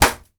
punch_blocked_03.wav